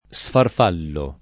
sfarfallo [ S farf # llo ]